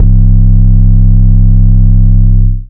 808 4 {C} [ motorsport ].wav